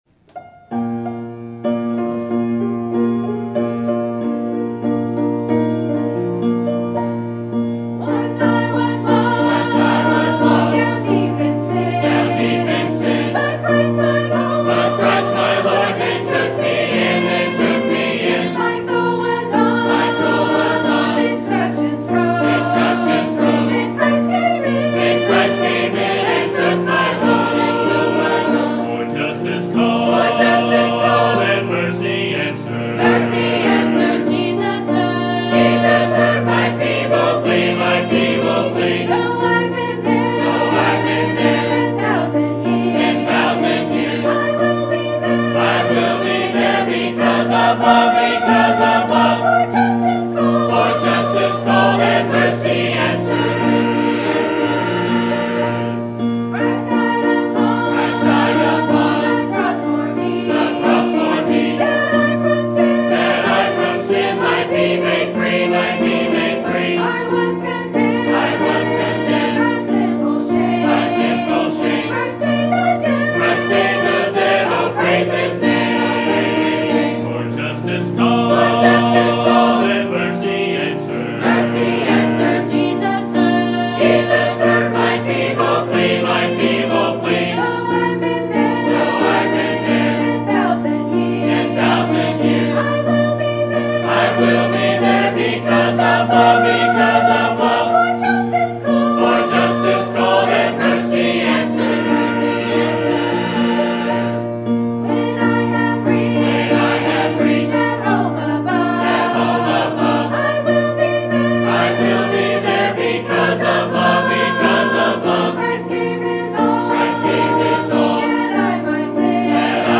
Sermon Topic: General Sermon Type: Service Sermon Audio: Sermon download: Download (31.32 MB) Sermon Tags: John Freedom Believed Truth